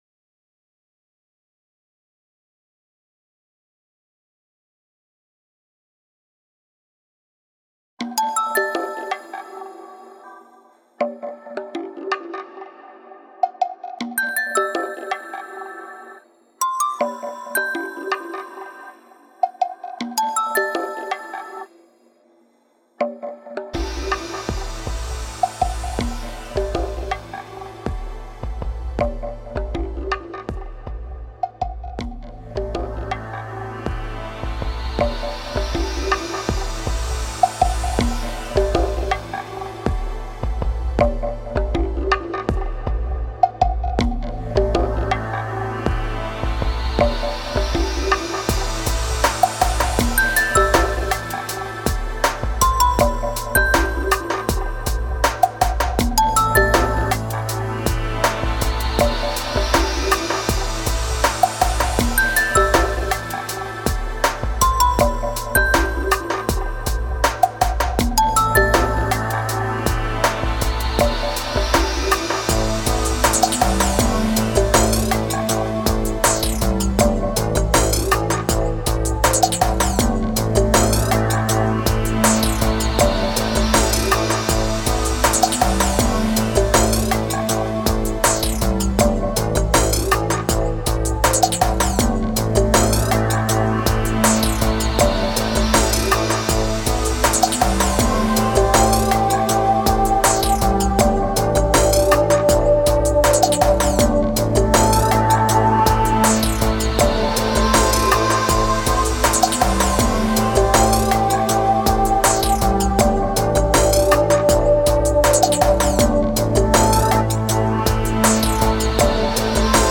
NEW  BACKGROUND AMBIANT MUSIC